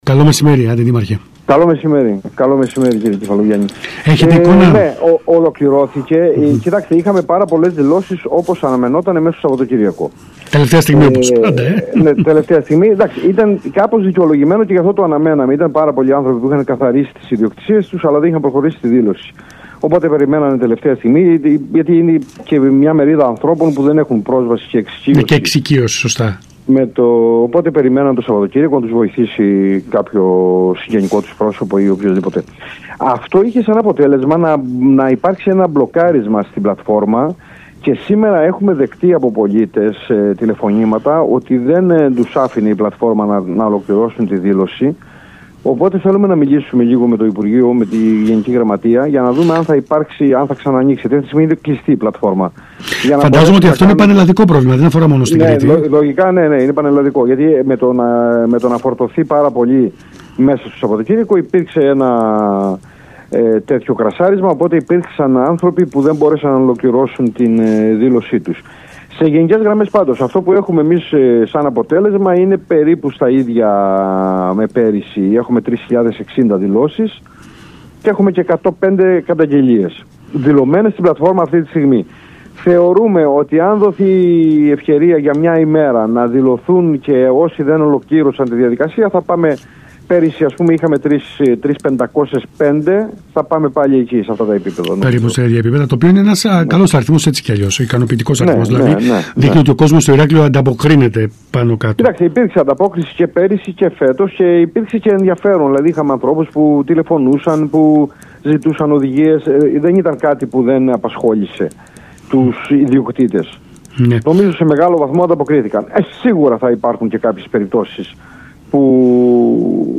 Ακούστε εδώ όσα είπε ο Αντιδήμαρχος Πολιτικής Προστασίας Μανώλης Χαιρέτης στον ΣΚΑΙ Κρήτης 92.1: